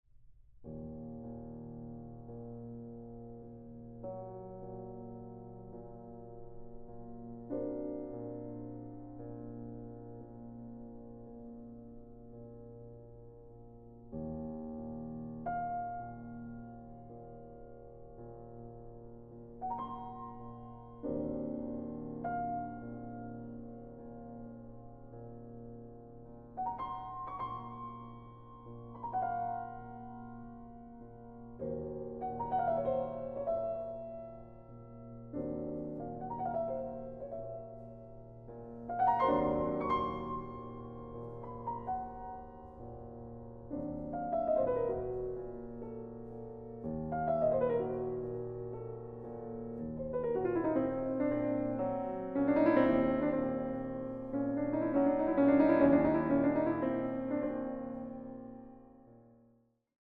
Klavier
Aufnahme: Mendelssohnsaal, Gewandhaus Leipzig